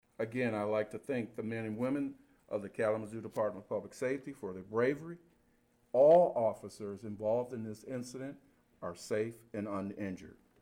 During a press conference held on Monday, March 21, Kalamazoo Department of Public Safety Police Chief Vernon Coakley gave his reassurance that the incident would receive a detailed and thorough investigation and review by the Michigan State Police.